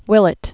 (wĭlĭt)